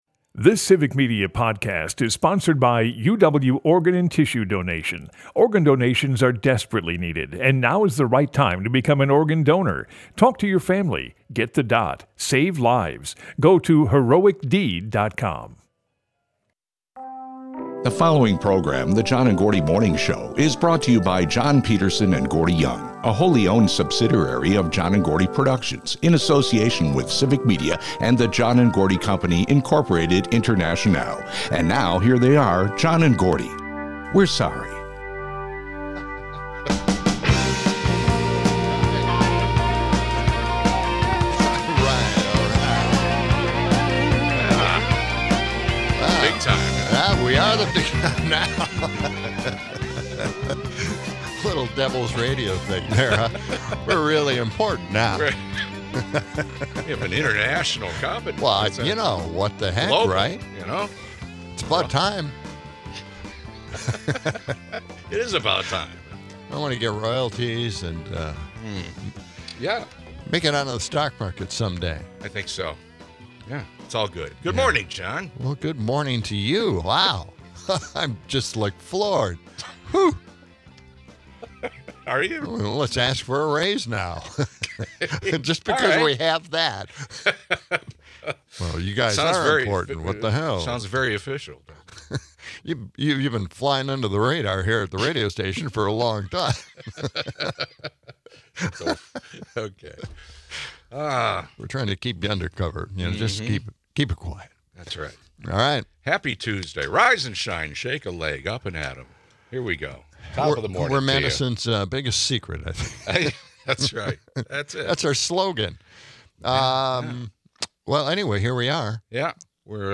Broadcasts live 6 - 8am weekdays in Madison.